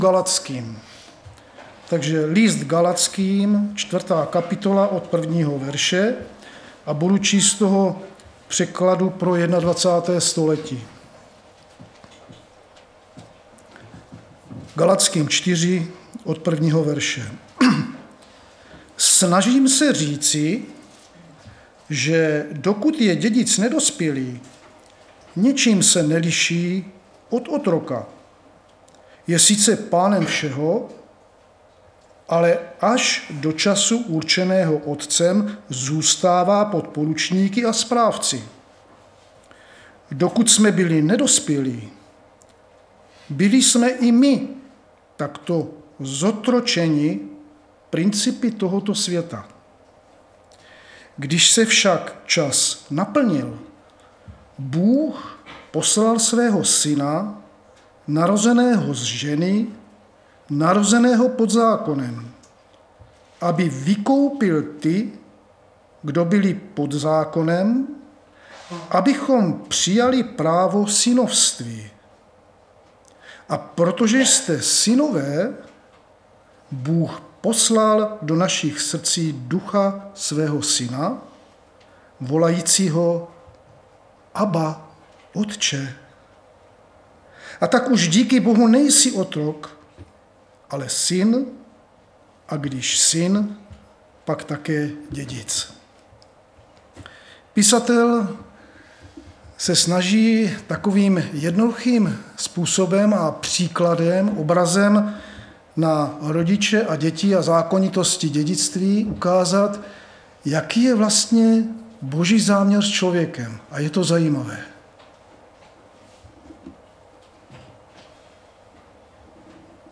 Nedělní vyučování
Záznamy z bohoslužeb